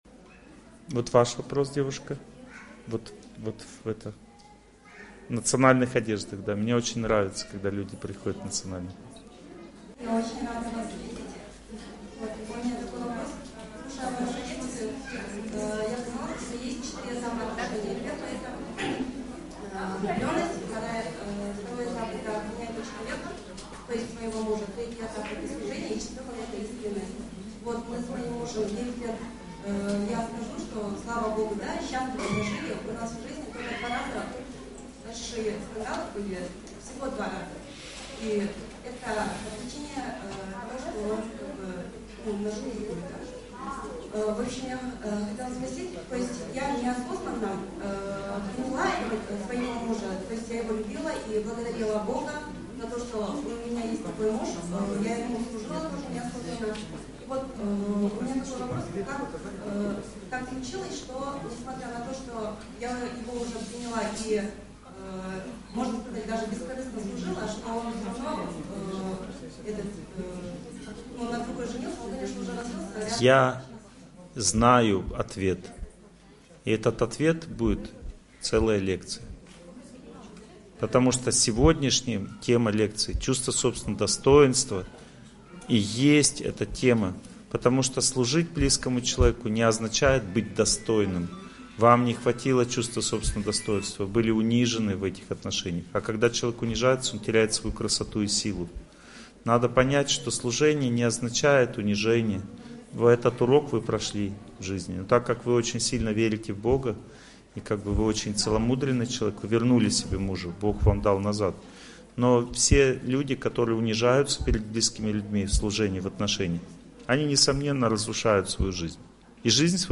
Chuvstvo-sobstvennogo-dostoinstva-sudba-uspeh-Lekciya-1.mp3